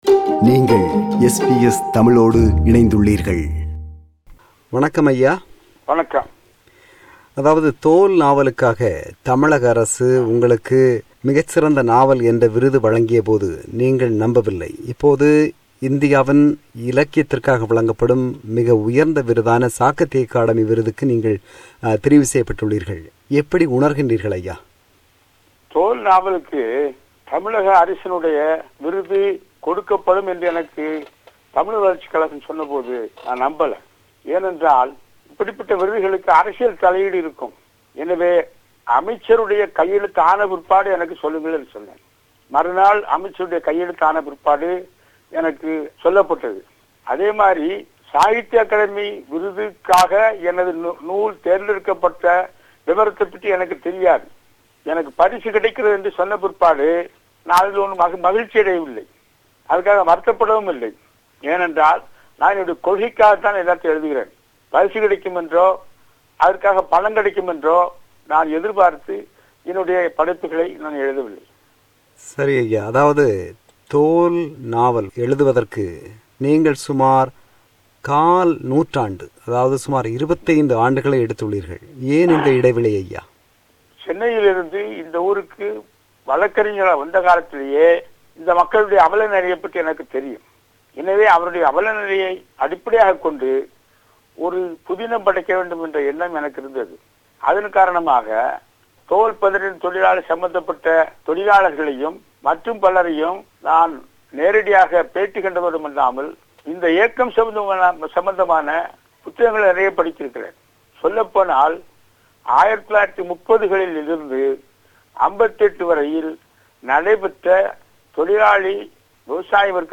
இந்த நாவலுக்காக அவருக்கு 2013 ஆம் ஆண்டு சாகித்ய அகாடமி விருது வழங்கப்பட்டது. அவ்வேளையில் அவர் நமக்கு வழங்கிய நேர்முகம் இது.